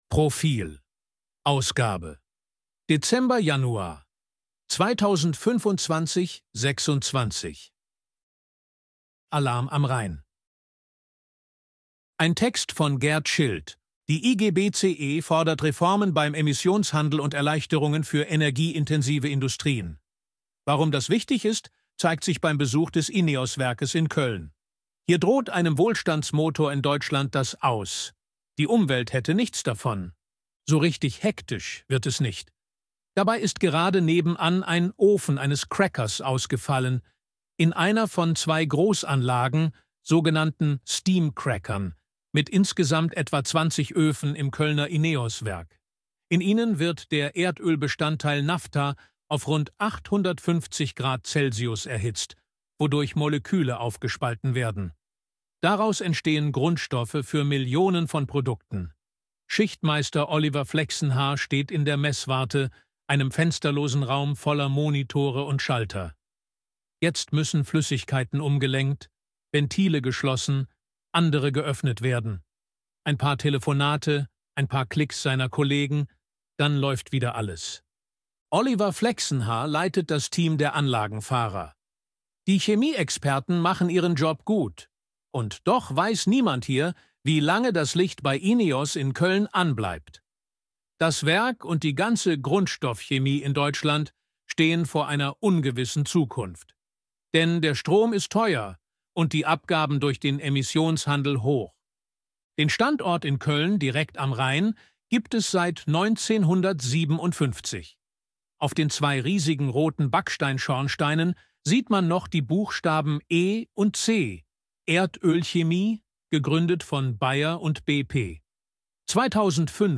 Artikel von KI vorlesen lassen ▶ Audio abspielen
ElevenLabs_256_KI_Stimme_Mann_Reportage.ogg